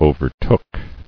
[o·ver·took]